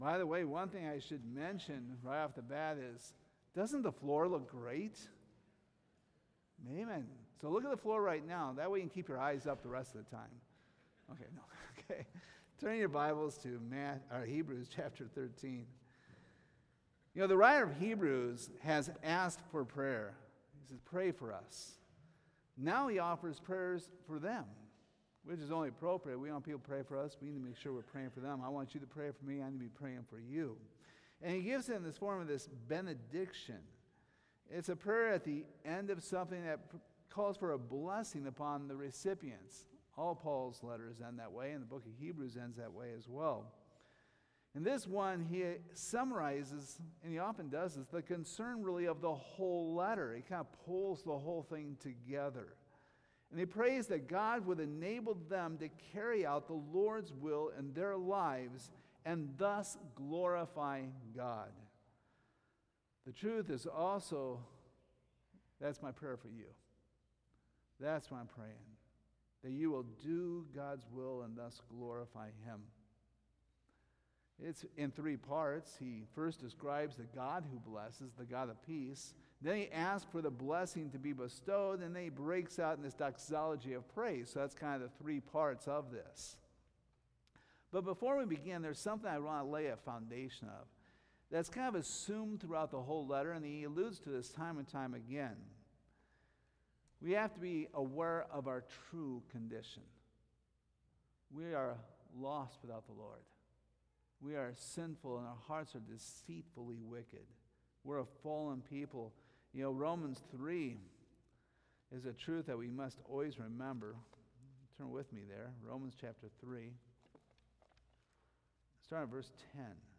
Hebrews 13:20-21 Service Type: Sunday Morning We hope you were blessed and challenged by the ministry of Calvary Baptist Church.